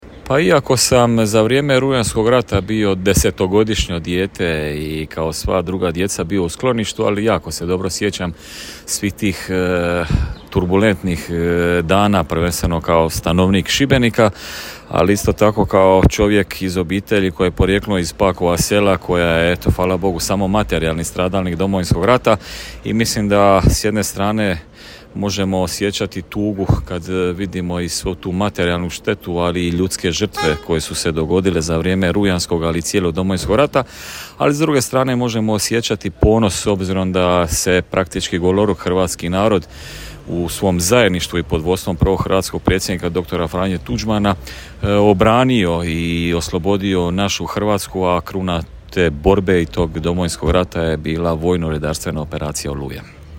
U organizaciji Općine Promina jutros je u Puljanima i Oklaju odana počast prvim žrtvama u Domovinskom ratu.
Župan Paško Rakić u Oklaju je danas kazao:
zupan-RAkic-1609-Oklaj.mp3